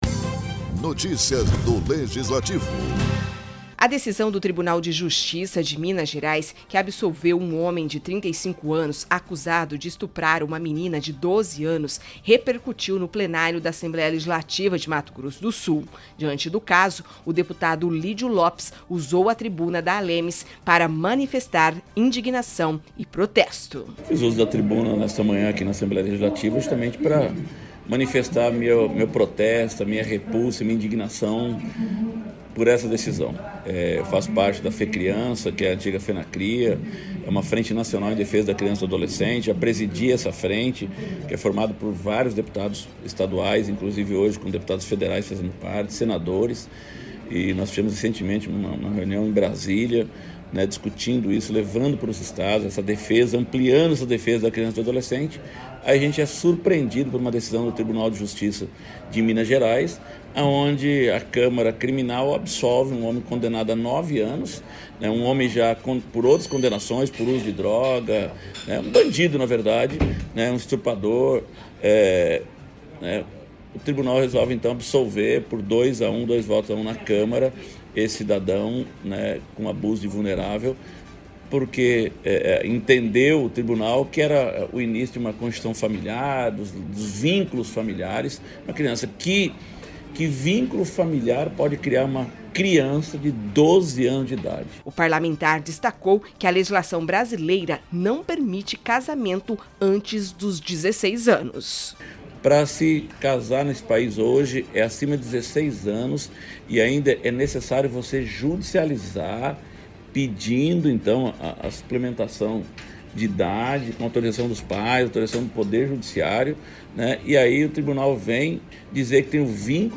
A decisão do Tribunal de Justiça de Minas Gerais que absolveu um homem de 35 anos acusado de estuprar uma menina de 12 anos repercutiu na Assembleia Legislativa. O deputado Lídio Lopes usou a tribuna para manifestar indignação.